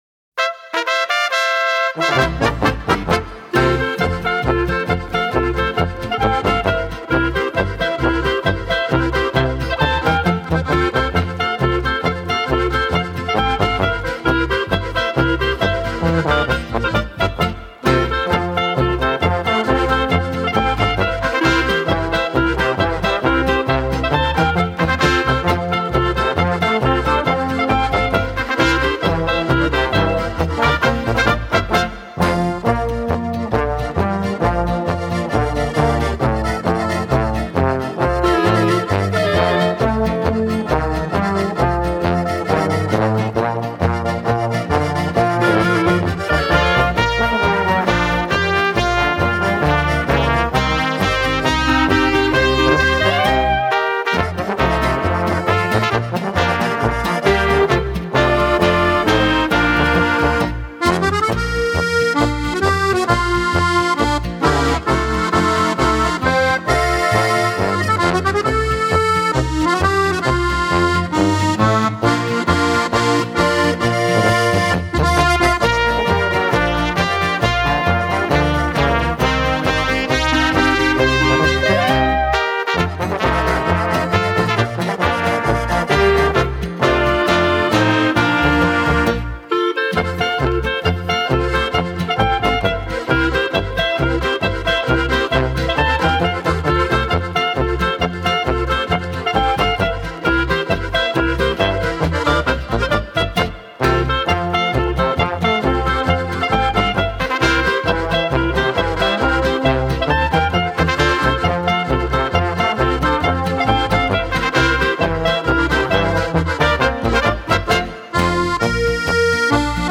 Gattung: für Oberkrainer Besetzung ab 5 bis 9 Musiker
Besetzung: Volksmusik/Volkstümlich Weisenbläser